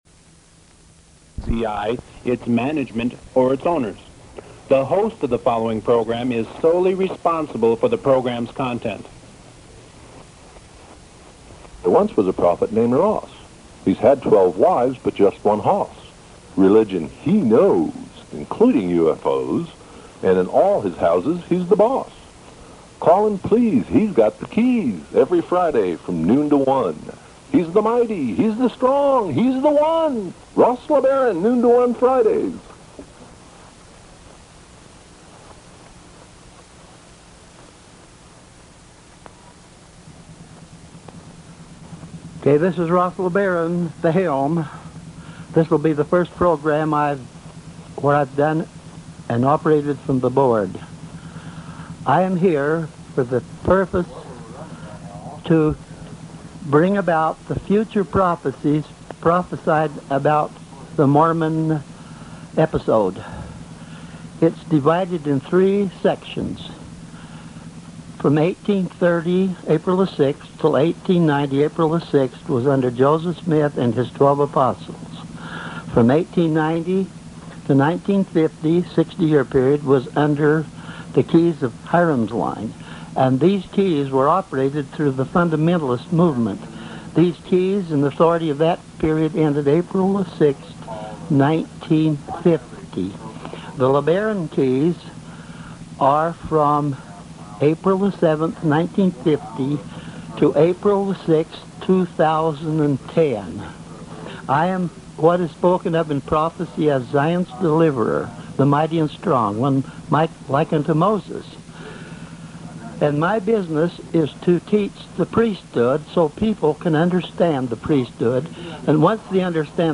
Posted byHolyOrder.OrgPosted inAudio, Radio show, TeachingsTags:, , , , , , , , , , , , , , , , , , , , , , , , , , , ,